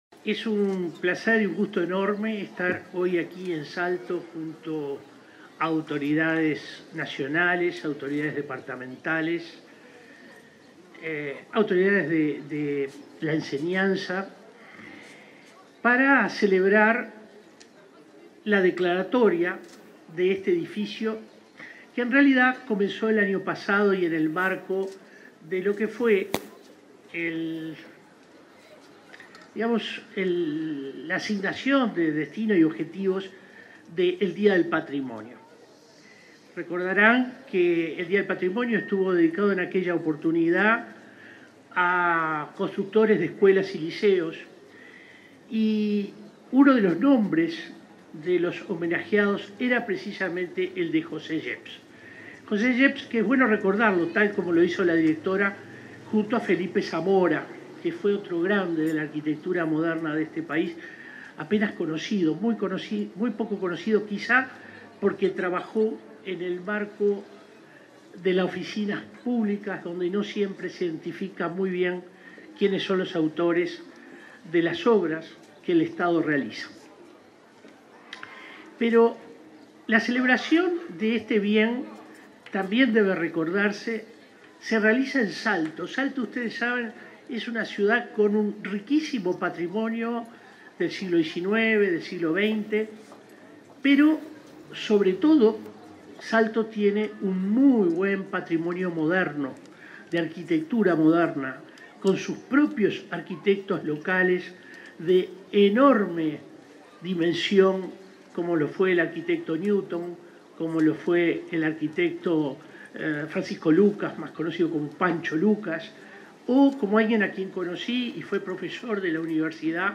En el marco del acto de declaratoria del liceo n.° 1 Instituto Politécnico Osimani-Llerena como Monumento Histórico Nacional, este 11 de abril, se